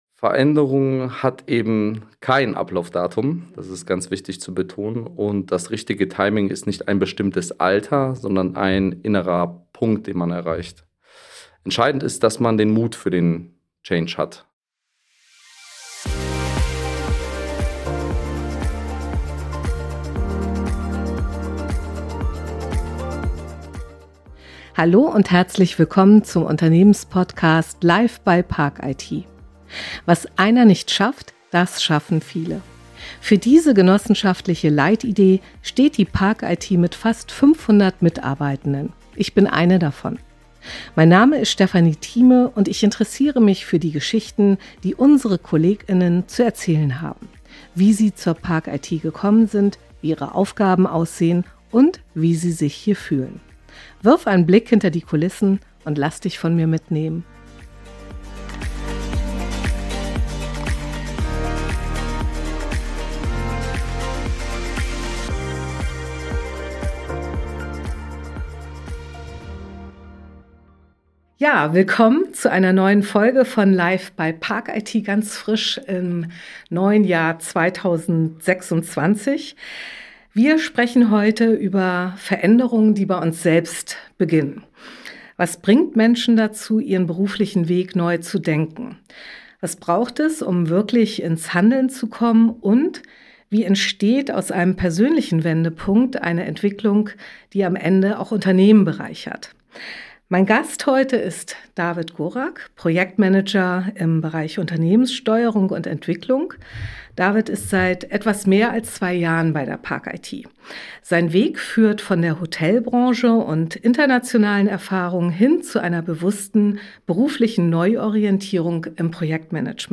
Change in eigener Sache: Haltung, die Unternehmen stärkt – Gespräch